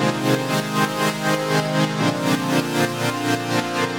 GnS_Pad-MiscA1:8_120-C.wav